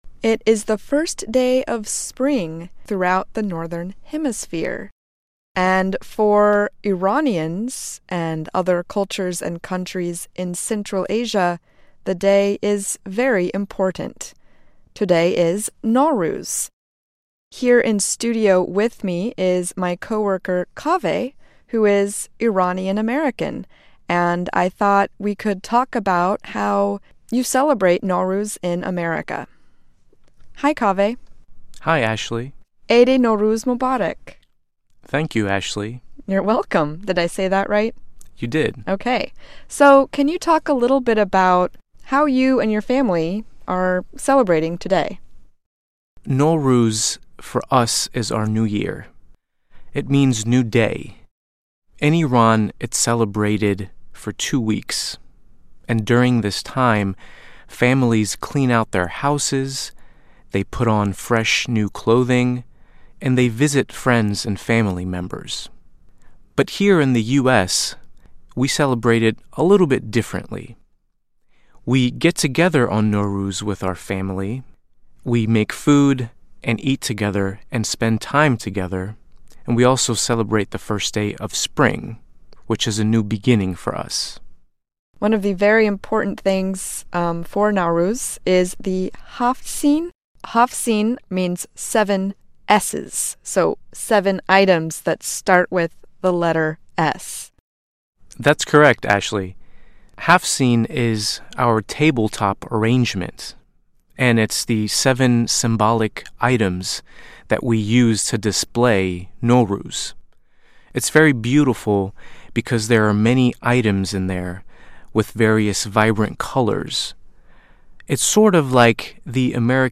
nowruz-in-america.mp3